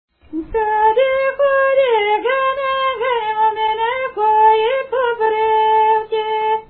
музикална класификация Песен
форма Двуредична с рефрен (R)
размер Две четвърти
фактура Едногласна
начин на изпълнение Солово изпълнение на песен
битова функция На хоро
фолклорна област Югоизточна България (Източна Тракия с Подбалкана и Средна гора)
начин на записване Магнетофонна лента